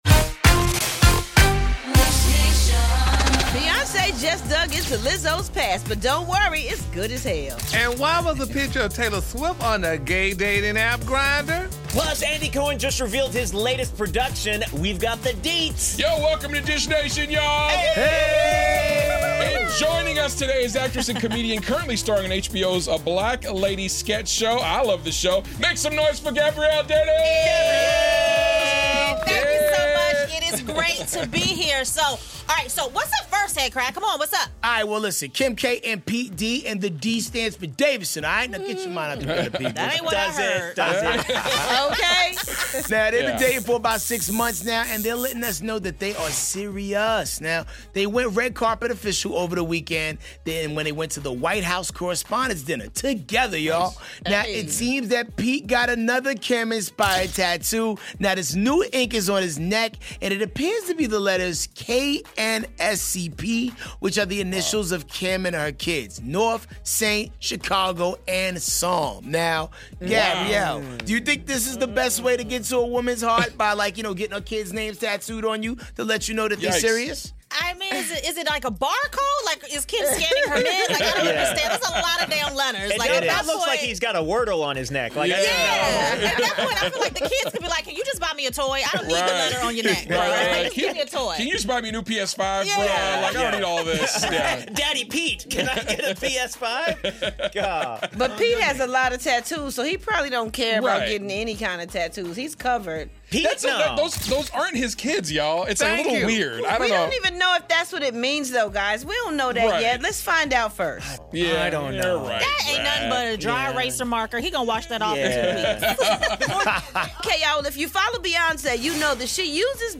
Pete Davidson and Kim Kardashian make their red carpet debut, Beyoncé finds Lizzo's baby pic, and does Katie Holmes have a new beau after her breakup with Jamie Foxx? Comedian and actress Gabrielle Dennis cohosts, so tune in to today's Dish Nation for the dish!